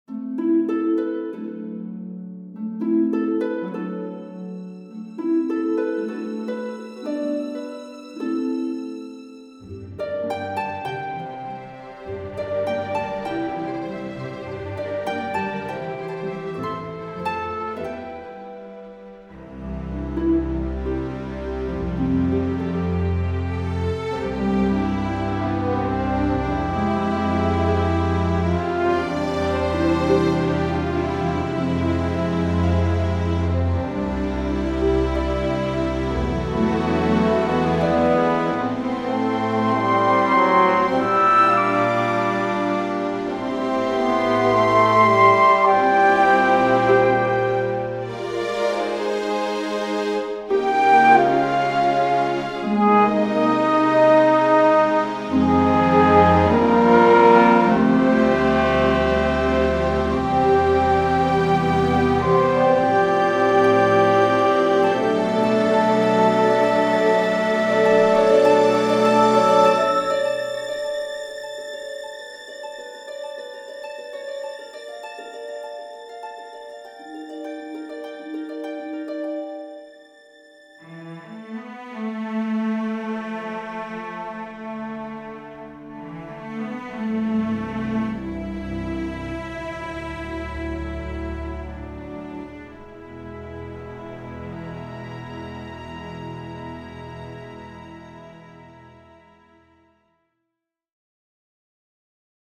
Game Music Demos / 遊戲配樂 1:58 1.
2.+Basement+Theme+(Peaceful).mp3